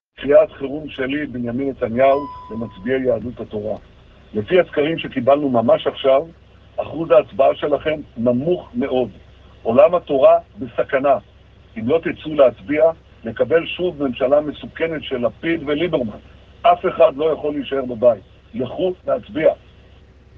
בעקבות הסקרים האחרונים המצביעים על היחלשות של מפלגת יהדות התורה, יו"ר הליכוד בנימין נתניהו שיגר הבוקר למאות אלפי טלפונים כשרים, הודעה מוקלטת הקוראת למצביעי המפלגה להתעורר.
ההודעה המוקלטת של נתניהו